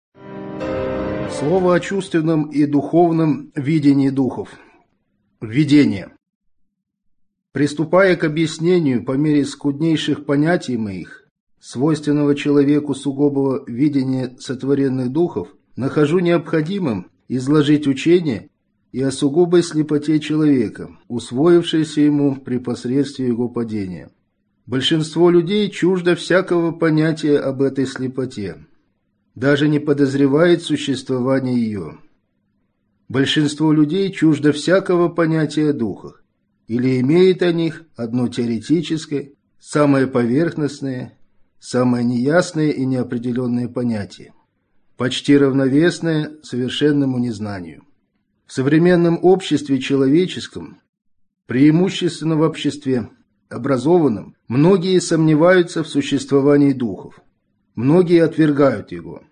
Аудиокнига Слово о смерти | Библиотека аудиокниг